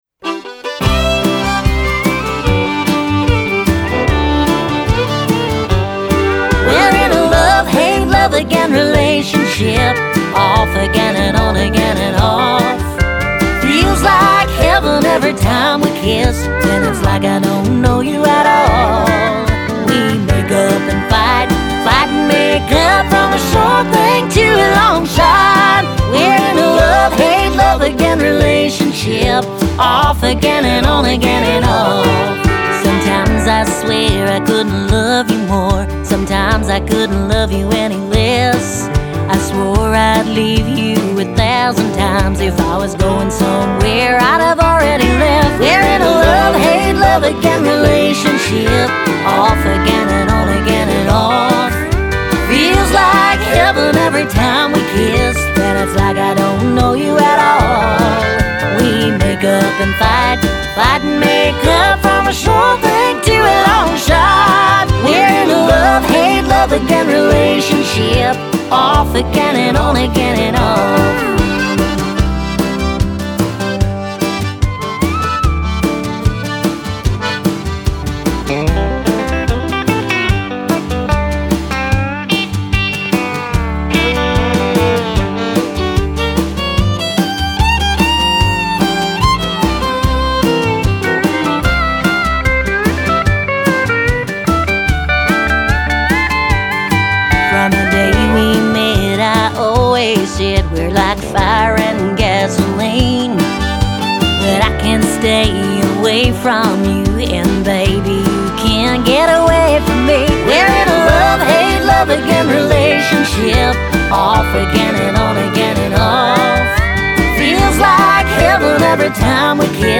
brings the good vibes and classic country sounds.